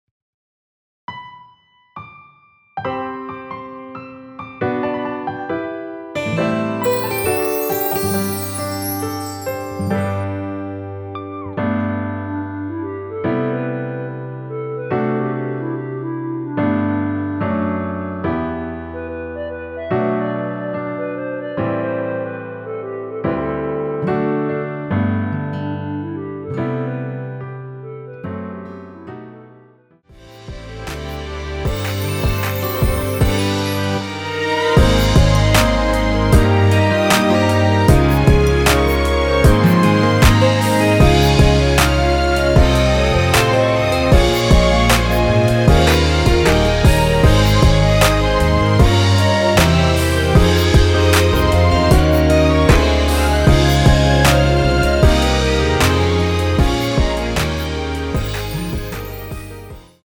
원키 멜로디 포함된 MR입니다.
멜로디 MR이라고 합니다.
앞부분30초, 뒷부분30초씩 편집해서 올려 드리고 있습니다.